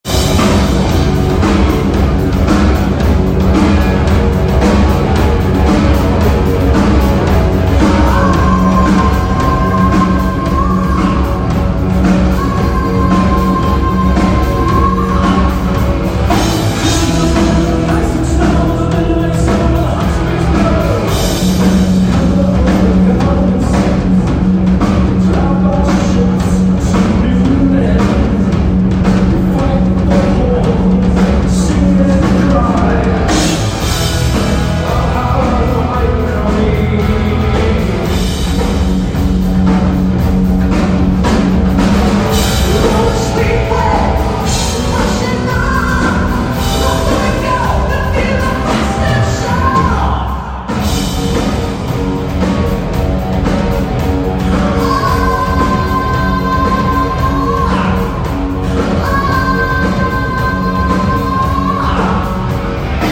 Classic rock
tribute band